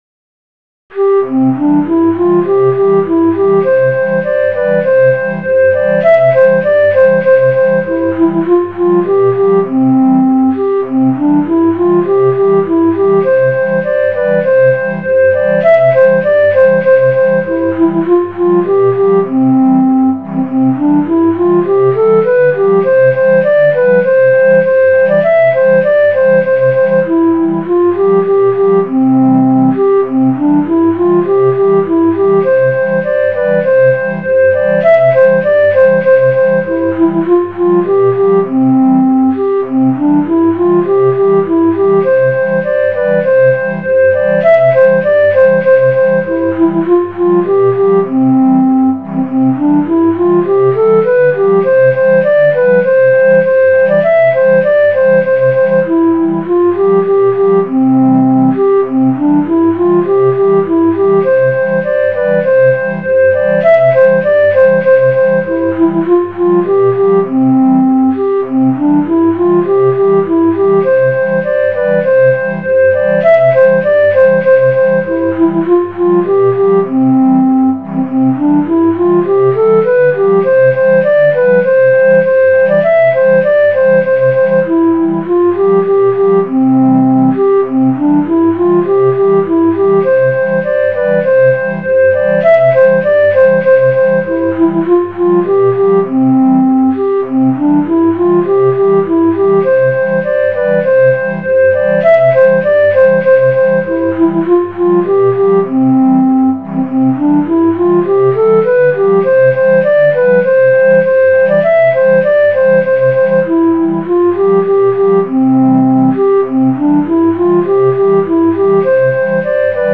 Midi File, Lyrics and Information to The Crocodile Song